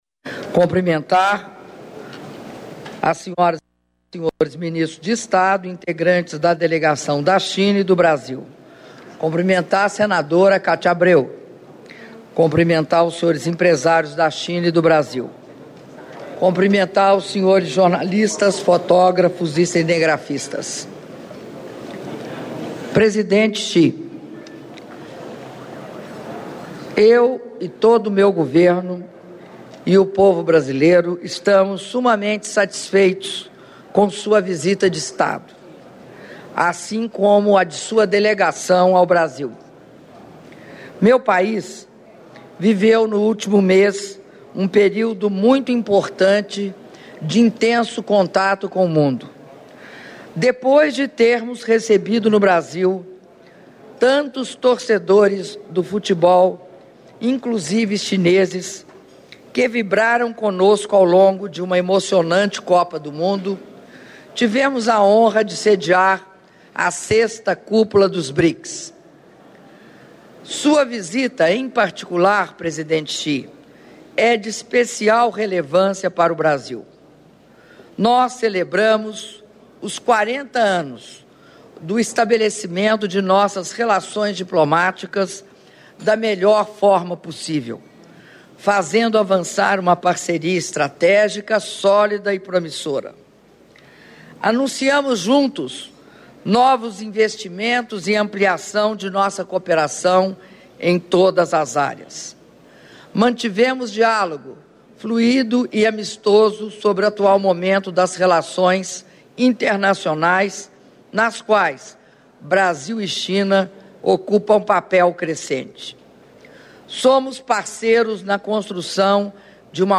Áudio das palavras da Presidenta da República, Dilma Rousseff, durante almoço em homenagem ao Presidente da República Popular da China, Xi Jinping - Brasília/DF (05min30s)